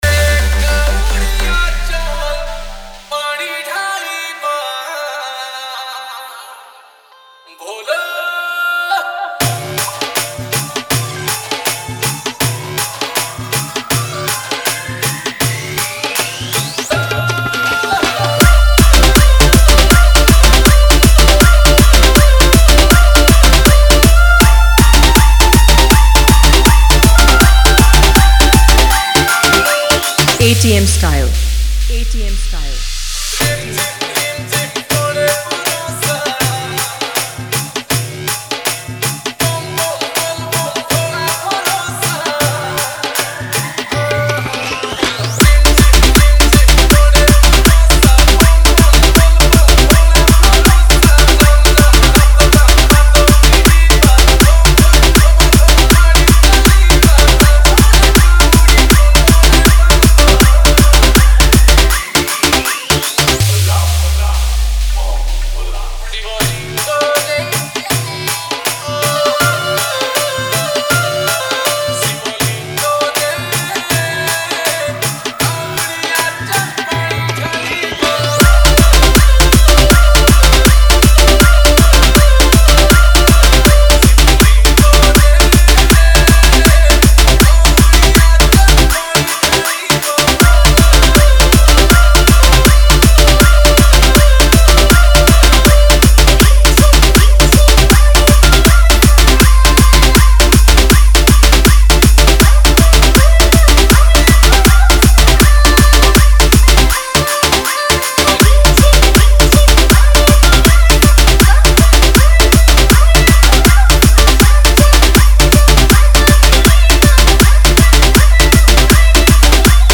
Bolbum Special Dj Song
Bhajan Dj Remix